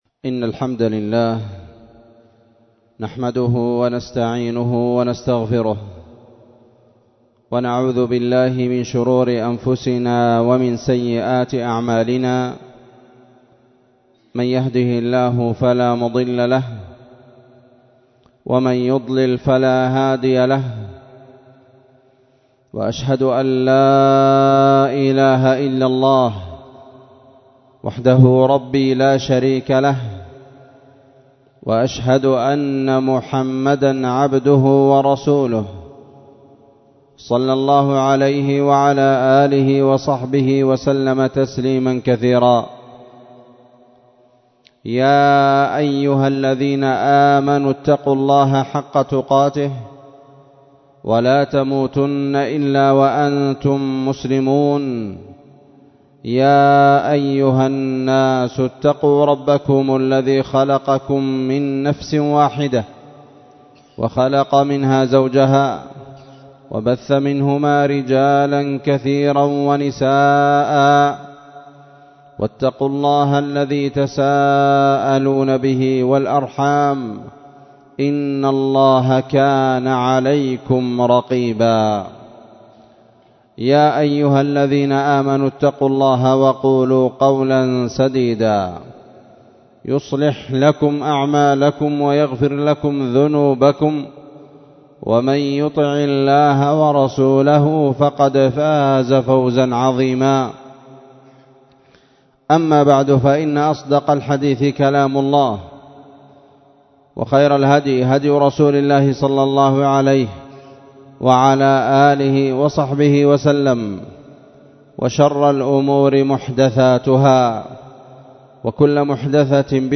خطبة جمعة بعنوان *فضائل الصحابة رضي الله عنهم أجمعين والرد على الطاعنين في الصحابي الجليل معاوية رضي الله عنه*